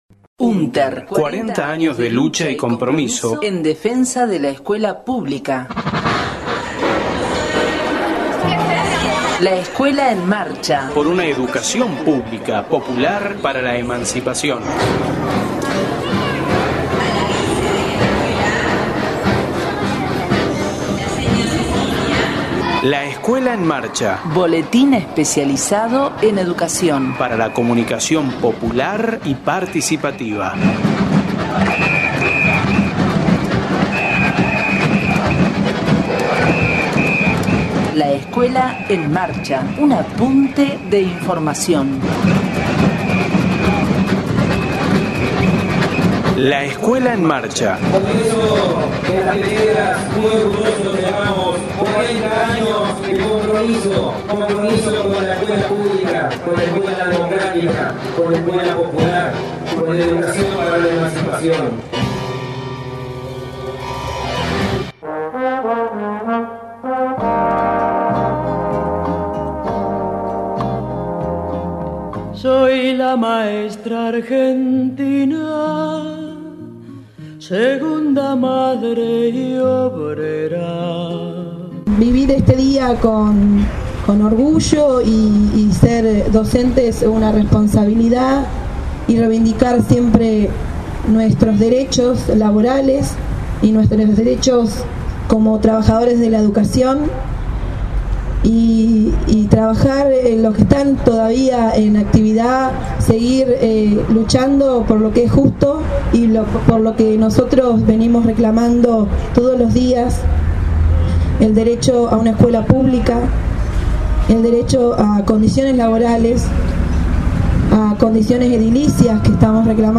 LEEM, radio 12/09/14: Sobre el Día del Maestro y la Maestra